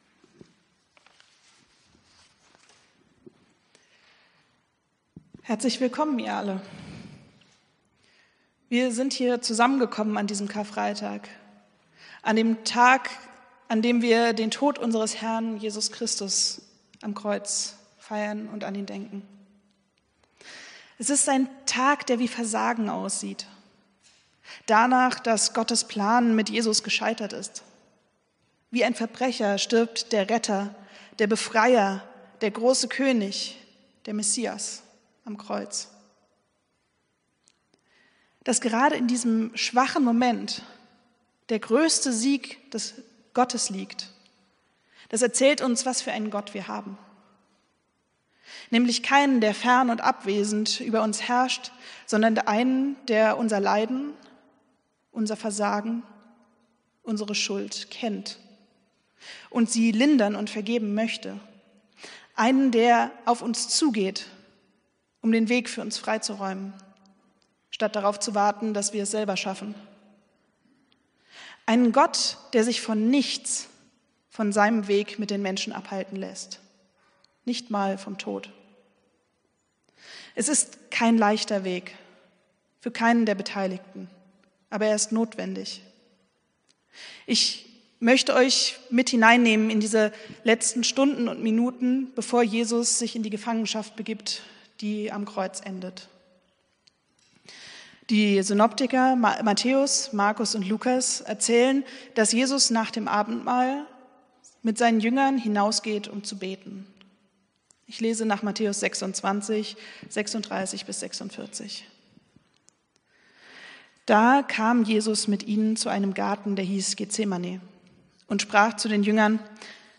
Predigt vom 18.04.2025